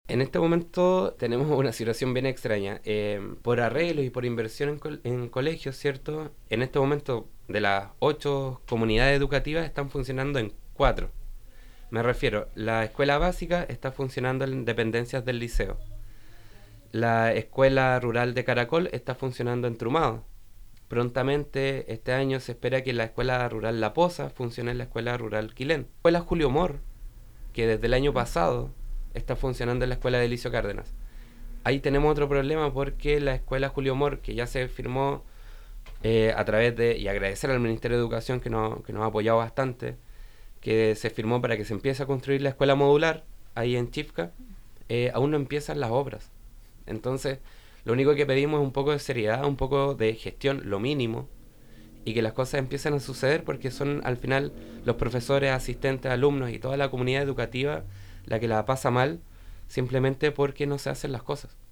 Del mismo modo, Fabián Cortez indicó que las ocho comunidades educativas de San Pablo se encuentran funcionando en cuatro establecimientos, recalcando que a pesar de tener los recursos para la escuela modular, aún no se comienza con las obras.